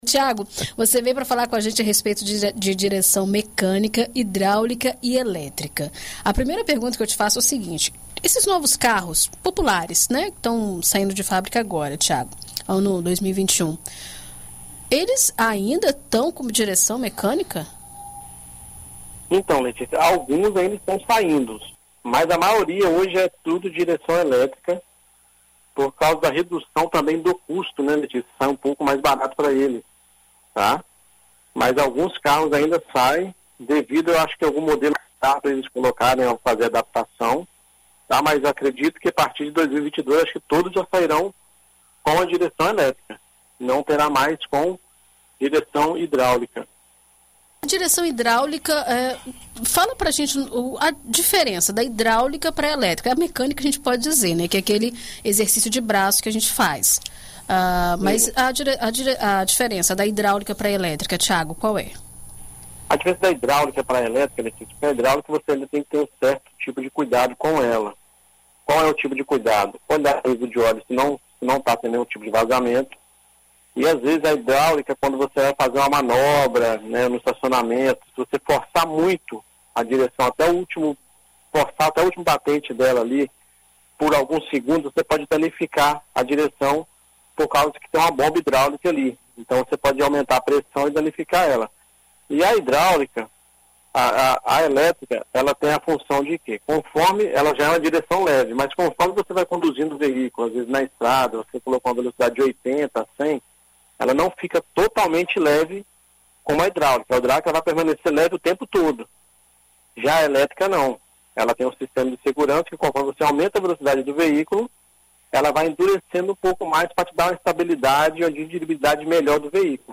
BandNews FM